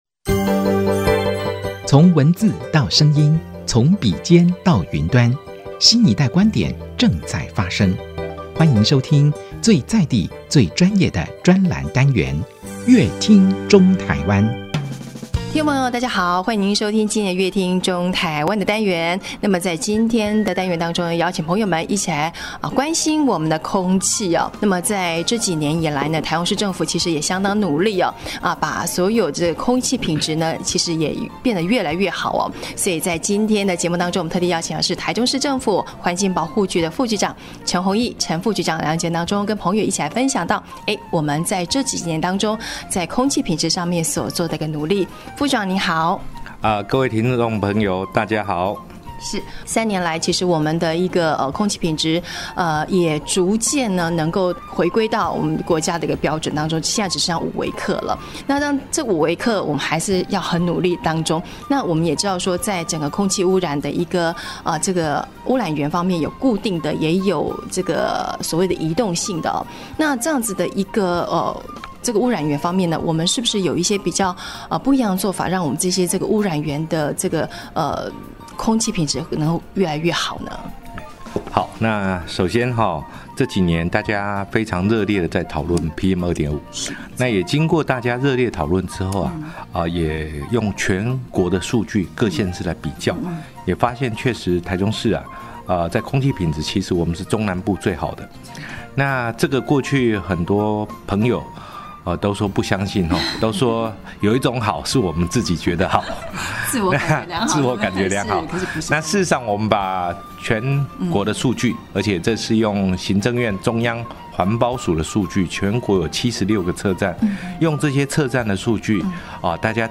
本集來賓：台中市政府環境保護局陳宏益副局長 本集主題：創意平安祈福販賣機 為宗教污染減量 本集內容： 看到「天